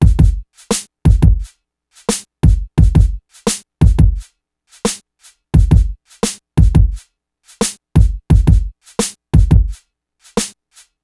hundreds of hip hop loops and construction kits from 75 bpm to 125 bpm This is a nice set of loops for only 5.99 700 mb worth of hip hop drum loops
87BPMB621.WAV